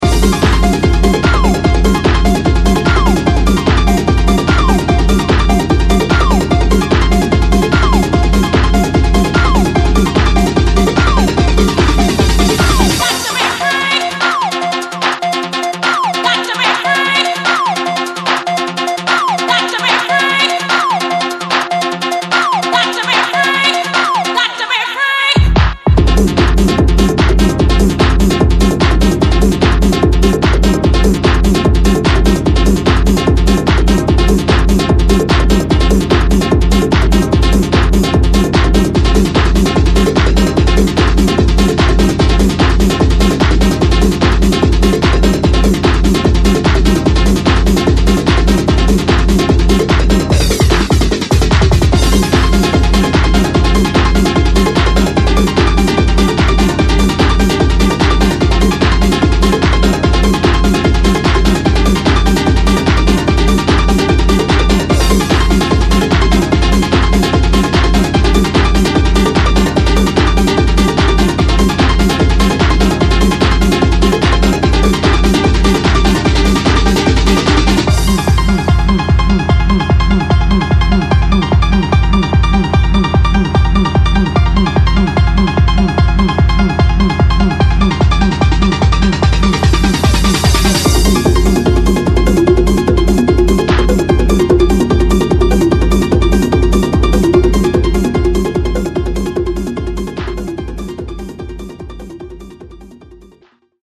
Hard-Trance